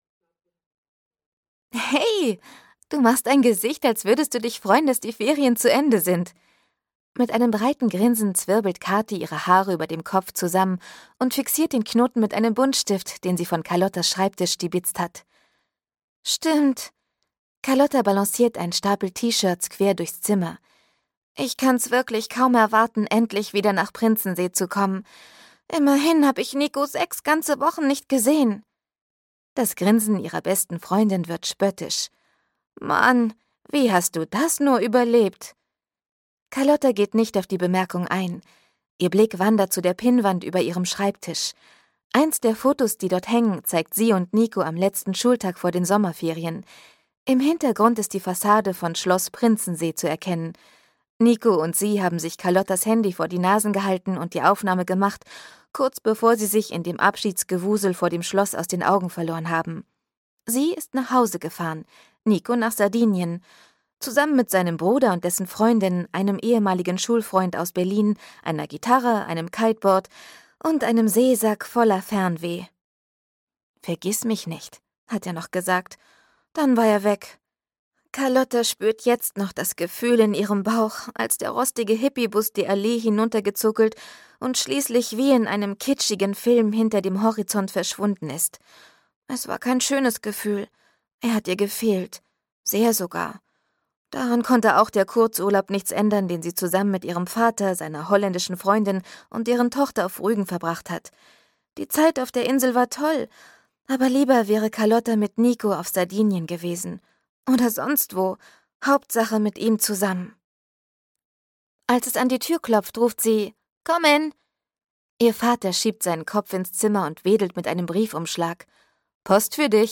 Carlotta 5: Carlotta - Internat und tausend Baustellen - Dagmar Hoßfeld - Hörbuch